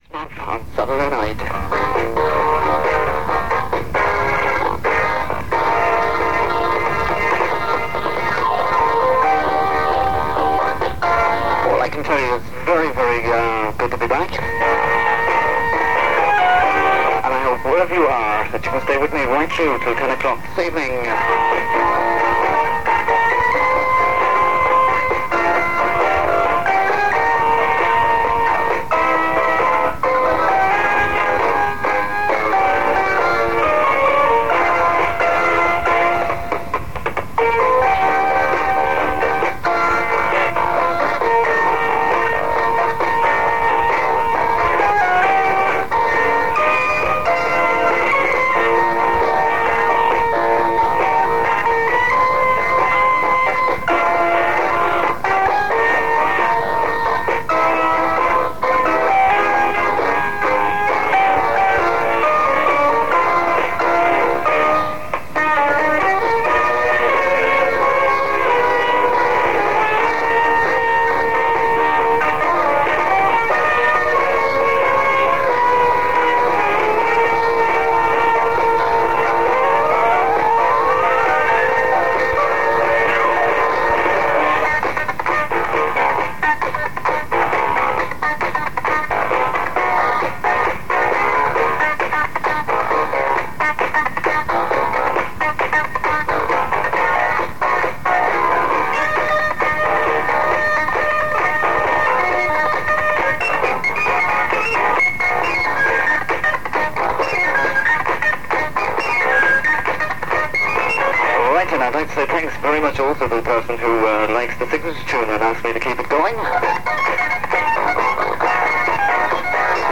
Audio quality is poor for the first part as it was made by placing a tape recorder against an AM radio. The second part was recorded from 95.5 FM using a radio-cassette recorder and seems to have been made at a later date.